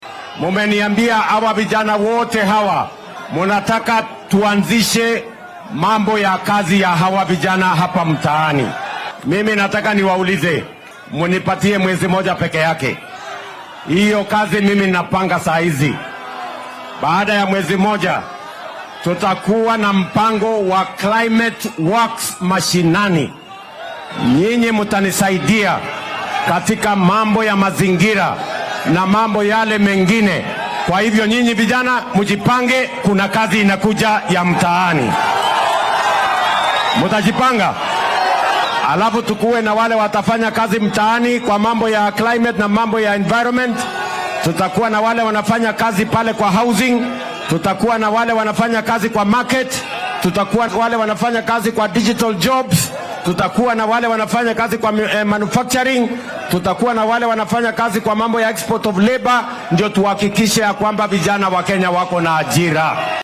Xilli uu ku sugnaa ismaamulka Kisumu ayuu madaxweyne William Ruto carrabka ku adkeeyay in dowladdu ay ka go’an tahay in fursado shaqo loo abuuro dhallinyarada.